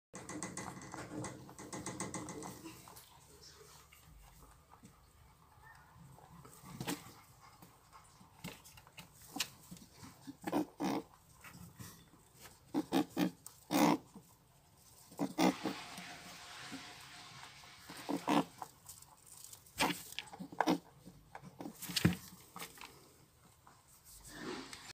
Звуки кроликов
В этом разделе собраны звуки кроликов, от раздраженного фырканья до нежного хрумканья морковкой.
• Качество: Высокое